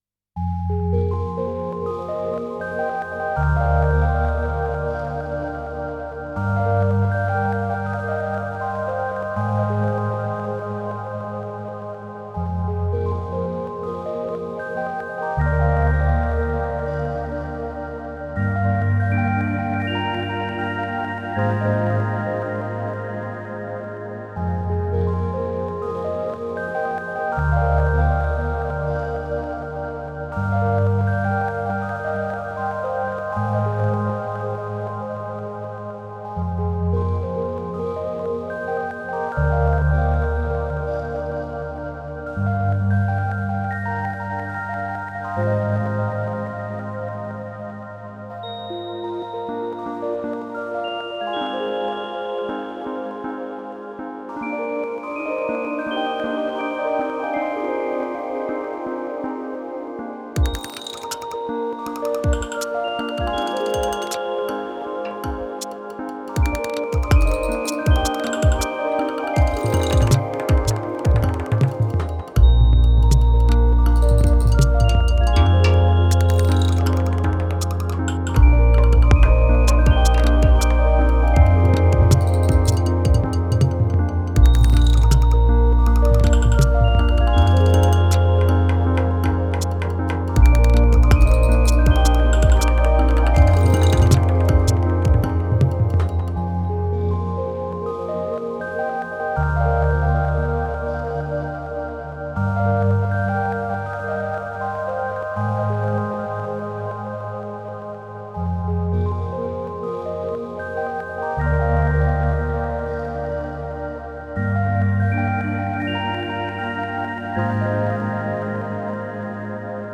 Textural layers swell with atmospheric tones.